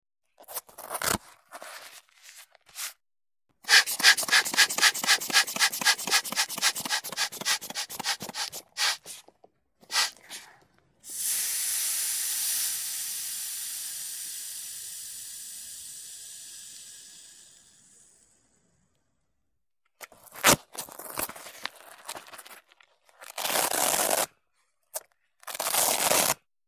Blood Pressure Sphygmomanometer | Sneak On The Lot